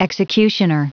Prononciation du mot executioner en anglais (fichier audio)
Prononciation du mot : executioner